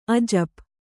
♪ ajap